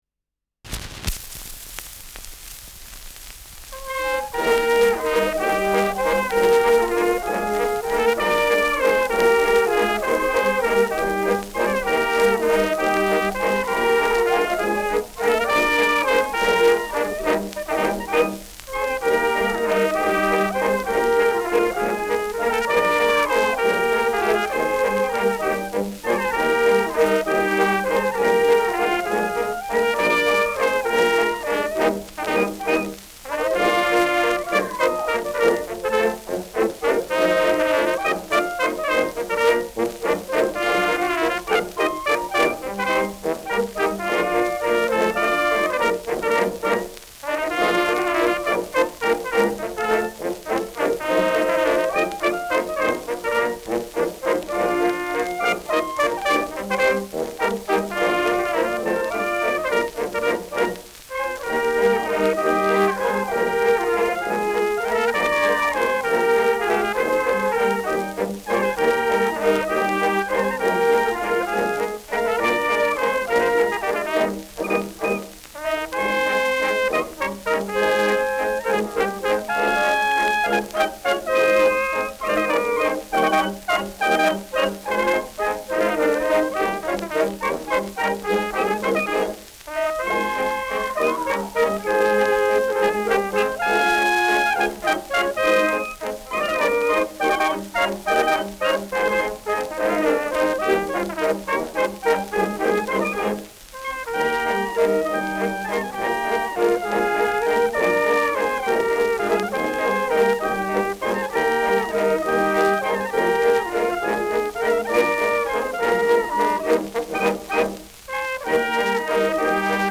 Schellackplatte
Abgespielt : Gelegentlich leichtes Knacken